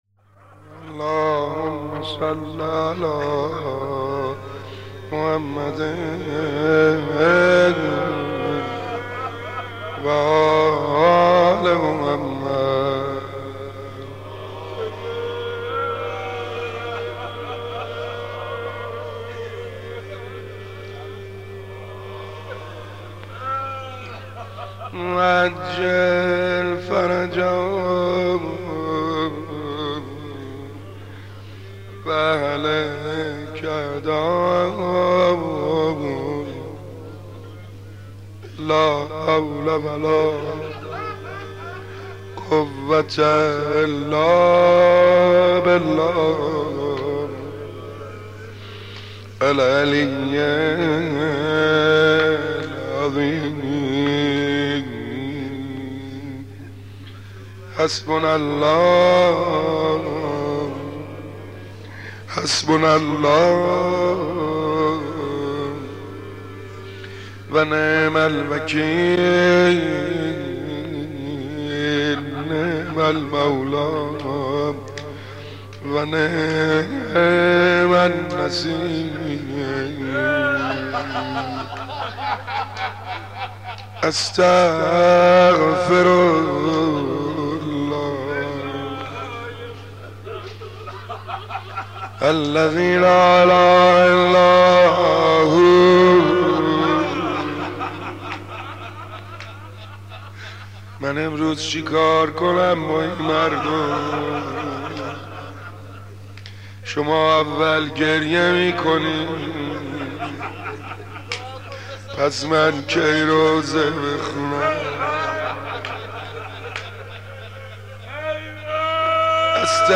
مداح
مناسبت : شب سوم محرم
مداح : سعید حدادیان